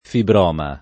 fibroma